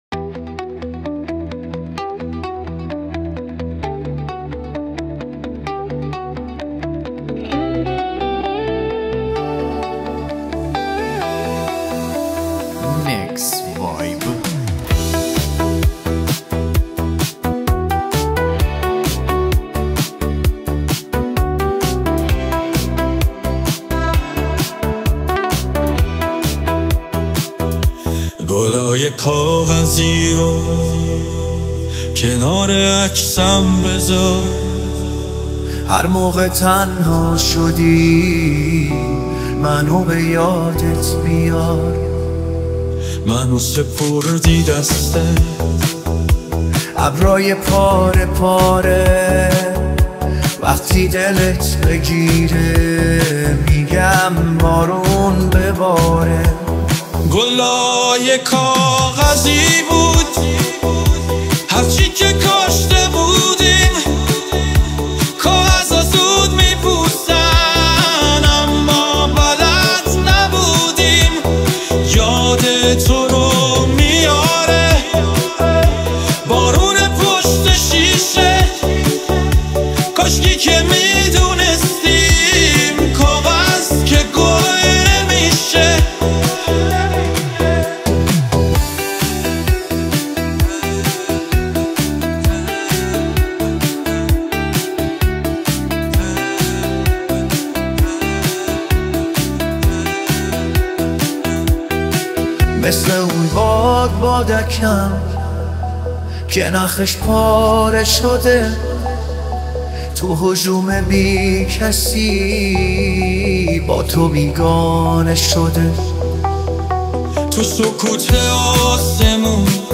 Музыка / 2026-год / Иранские / Клубная / Remix